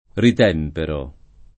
rit$mpero] — rarissimo l’uso nel senso di «ritemprare»: Sommergi, ritempera Nell’onde lustrali Le razze mortali [Somm$rJi, rit$mpera nell 1nde luStr#li le r#ZZe mort#li] (Zanella)